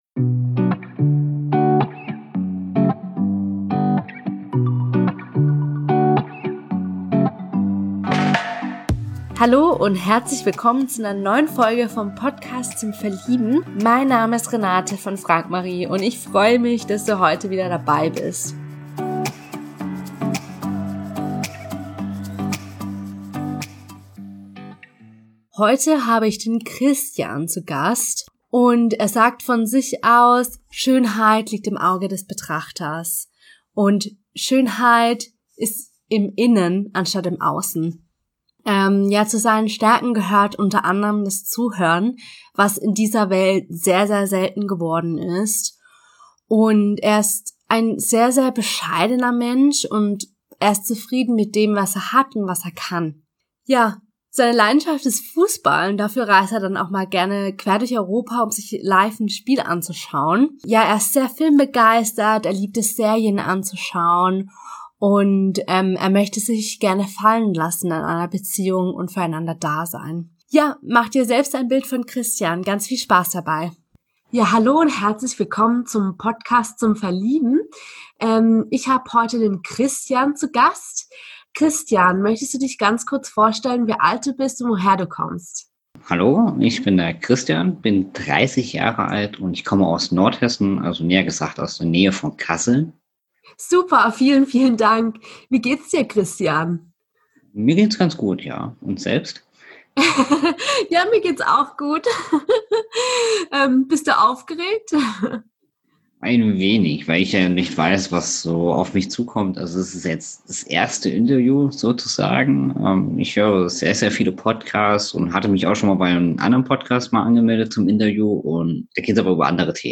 Im Podcast zum Verlieben interviewen wir für dich Singles zum Kennenlernen. Wir lassen Menschen in einem lockeren und inspirierenden Gespräch zu Wort kommen, die offen für die Liebe sind.